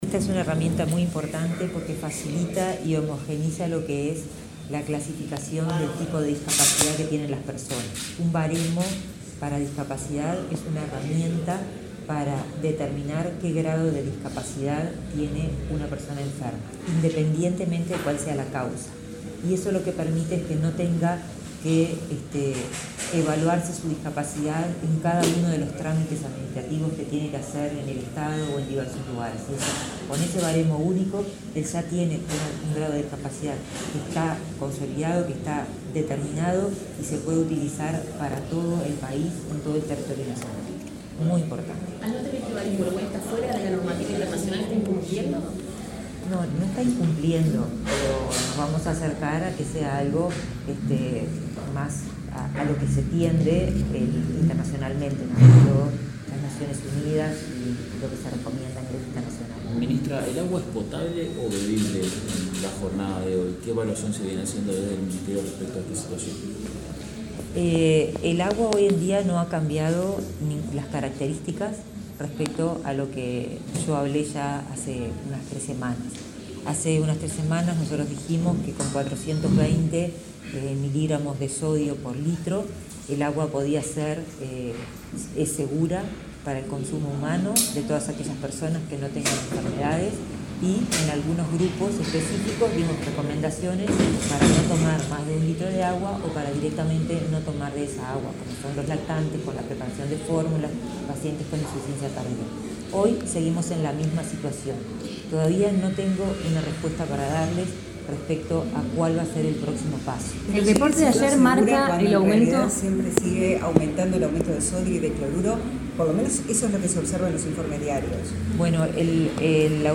Declaraciones de la ministra de Salud Pública, Karina Rando
La ministra de Salud Pública, Karina Rando, dialogó con la prensa luego de participar en la presentación de avances del baremo único de valoración y